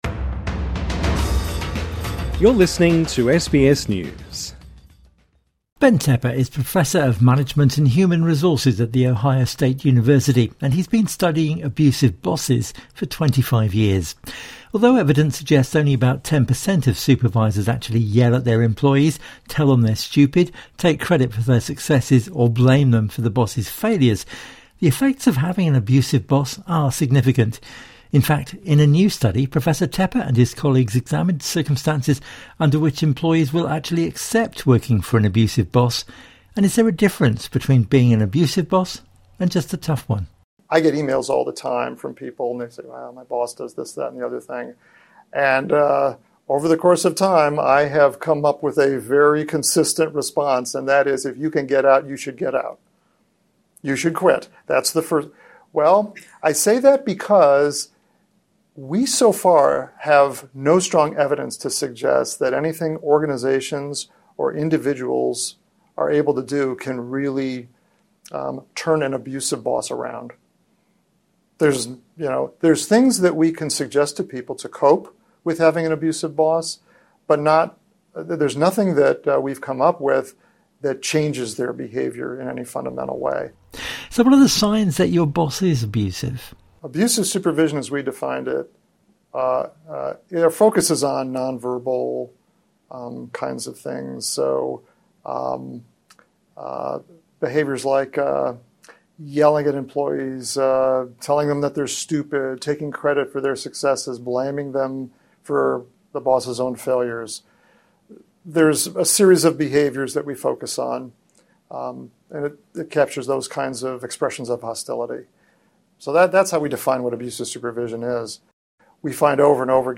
INTERVIEW: Is your boss a monster? Here's what to do.